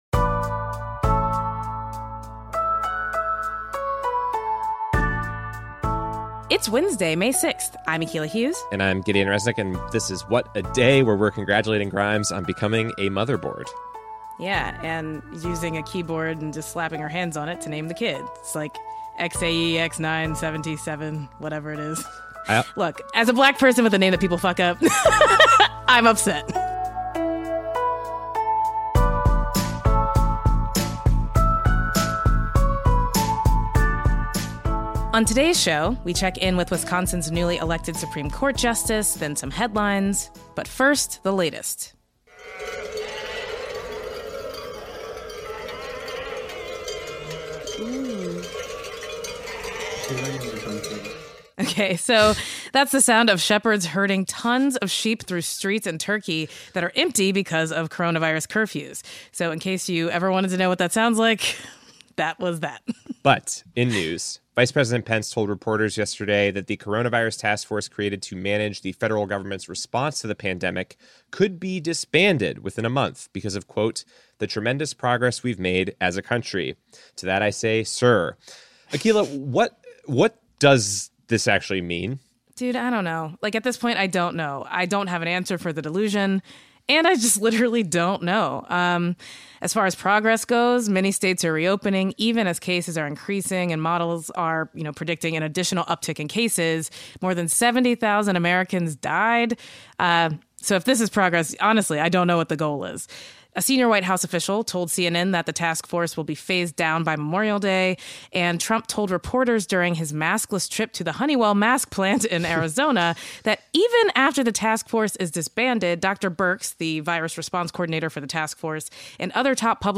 Wisconsin's Republican-controlled legislature is suing over the state's stay-at-home order, in a case that’s currently being heard by Wisconsin's conservative Supreme Court. We talk to Justice-elect Jill Karofsky, who will begin her term on that court in August.